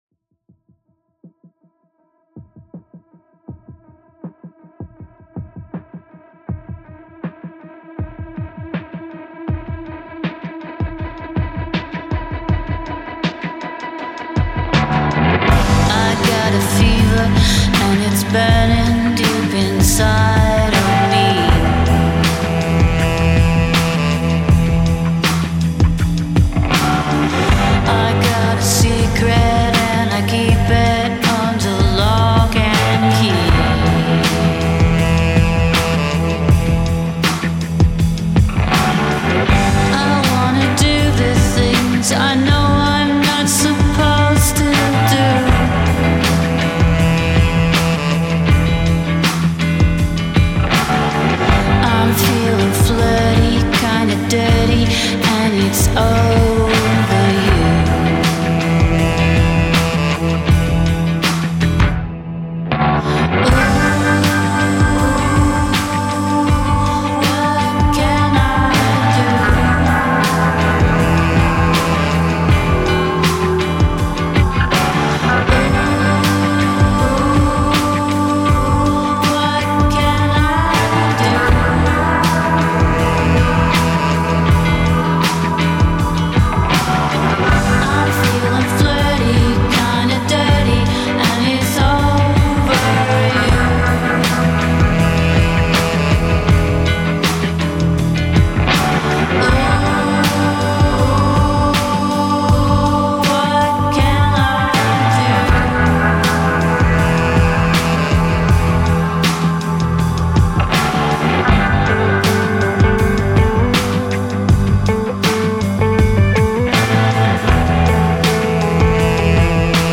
Tenor sax